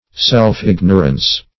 Search Result for " self-ignorance" : The Collaborative International Dictionary of English v.0.48: Self-ignorance \Self`-ig"no*rance\, n. Ignorance of one's own character, powers, and limitations.